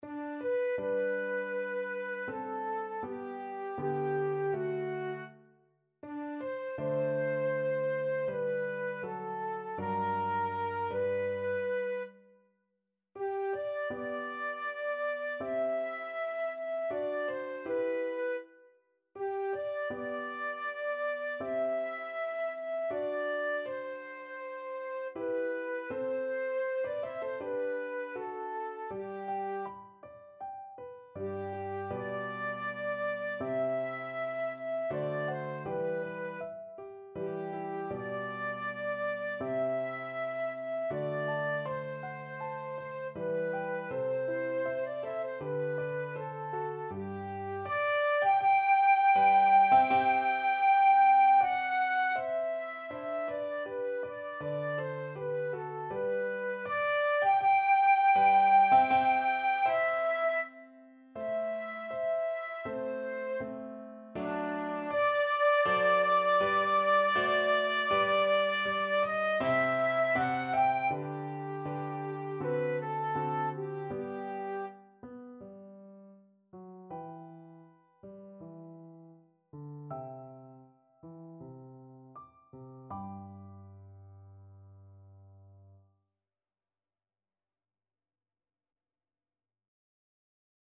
Free Sheet music for Flute
4/4 (View more 4/4 Music)
Andante
G major (Sounding Pitch) (View more G major Music for Flute )
Classical (View more Classical Flute Music)